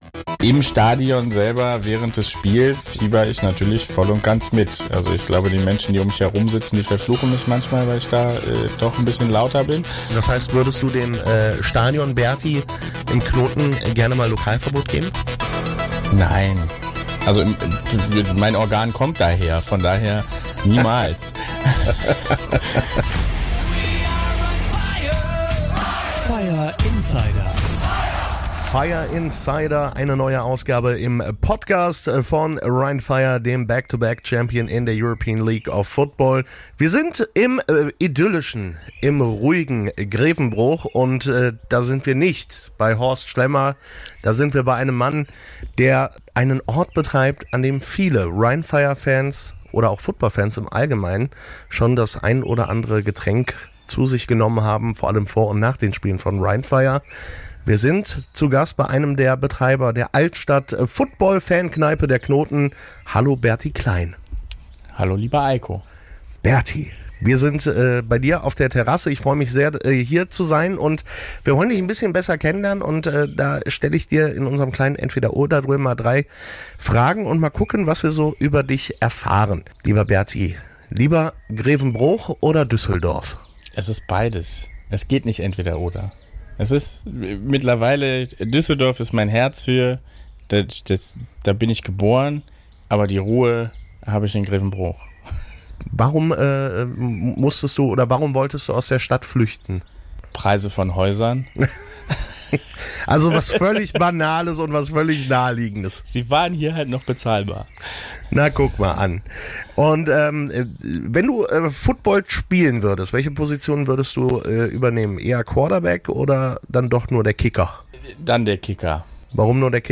Außerdem hört ihr die Stimmen zum Spiel in Innsbruck.